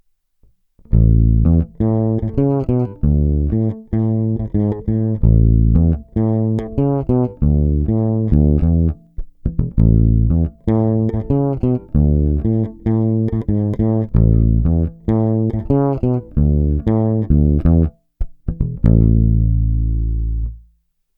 Není-li řečeno jinak, následující nahrávky jsou vyvedeny rovnou do zvukové karty, normalizovány a jinak ponechány bez dalších úprav.
Nejvíc se mi líbí zvuk, kdy snímač u krku je trochu stažený, cca o 1/4, kobylkový snímač je naplno, basy skoro naplno a výšky naopak skoro úplně stažené. Hráno v poloze pravé ruky nad krkovým snímačem.